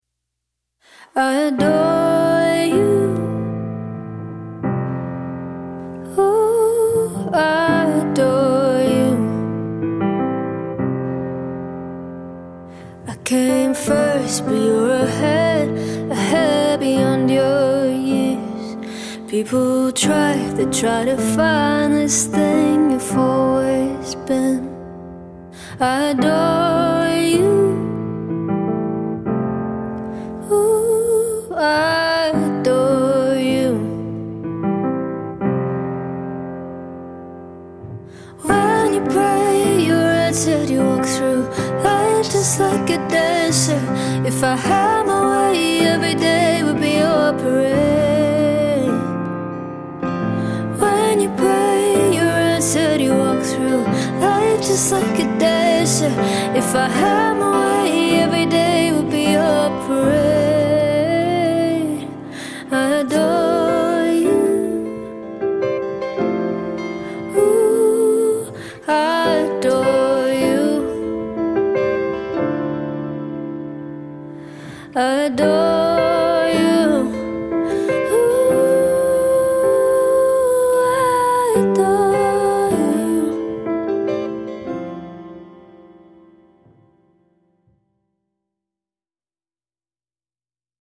Dual Vocals | Guitar, Keyboards | Looping | DJ | MC